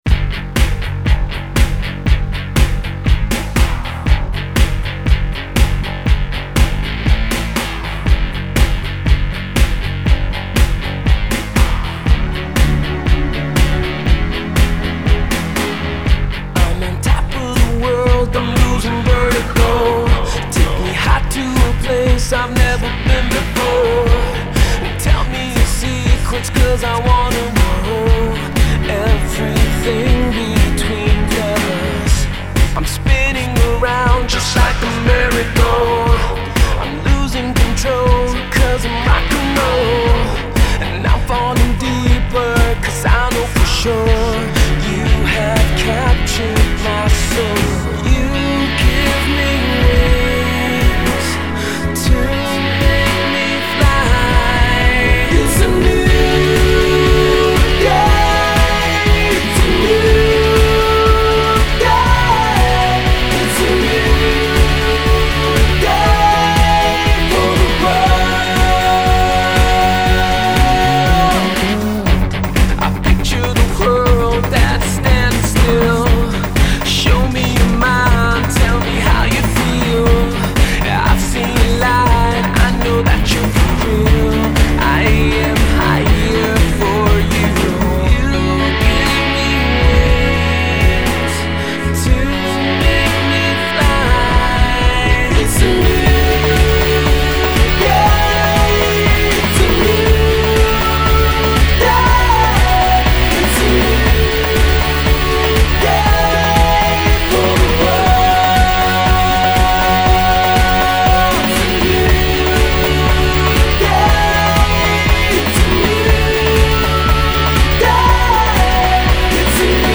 one of which is Nigeria’s very own prime indie band